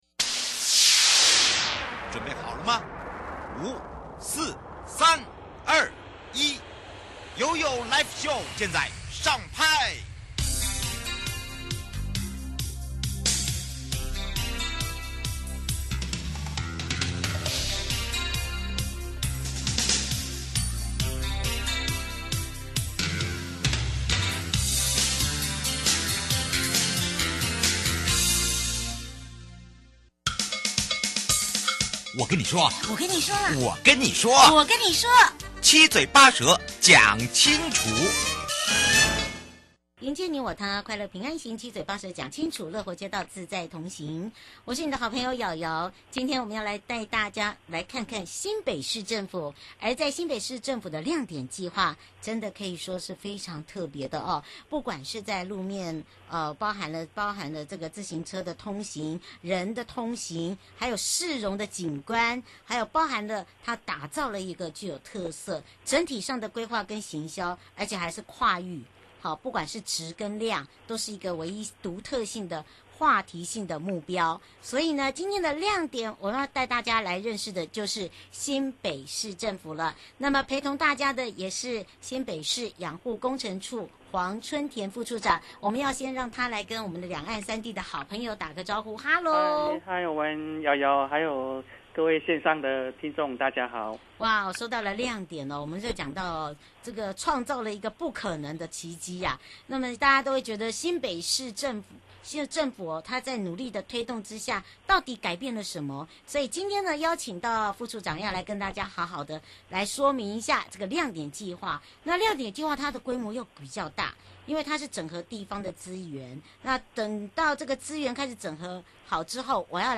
受訪者： 嘉義市政府-亮點計畫 (上集) 前瞻基礎建設計畫亮點計畫是屬於規模比較大，選點的位置跟最想改變的目標